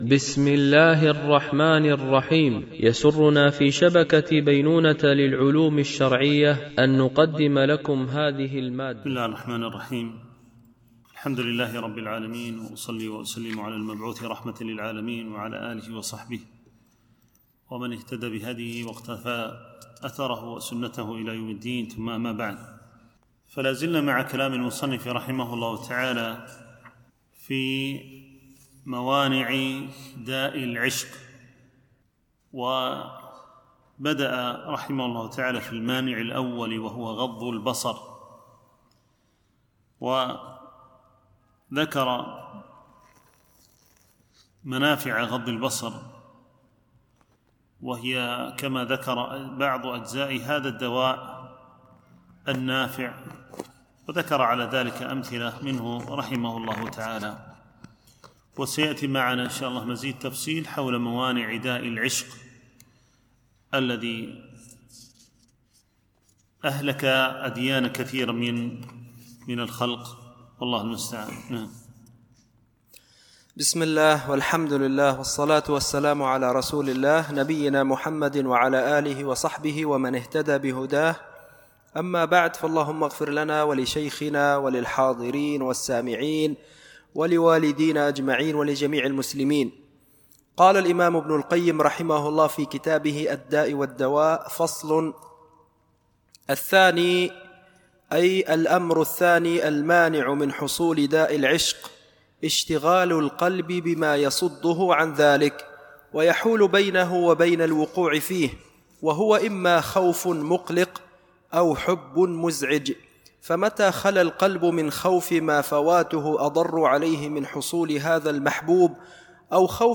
شرح كتاب الداء والدواء ـ الدرس 46